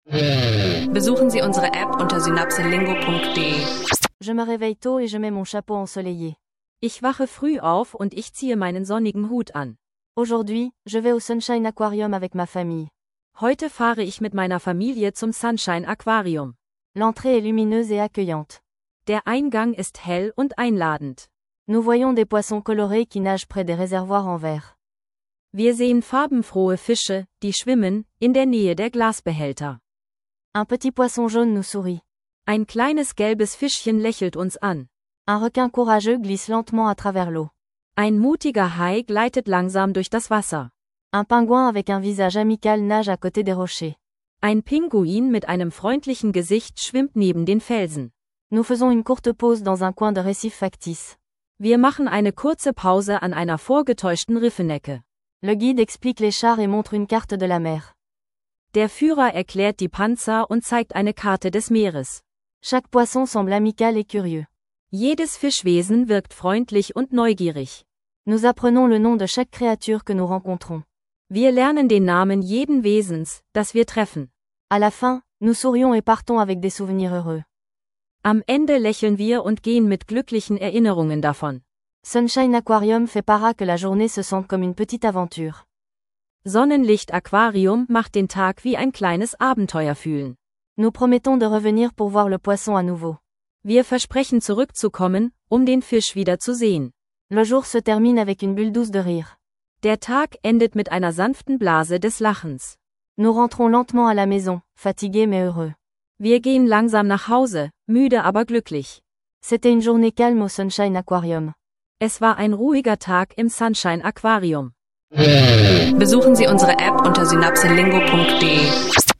Eine unterhaltsame Französisch-Lektion mit Alltagssprache, Vokabeln und Phrasen rund um einen Besuch im Sunshine Aquarium.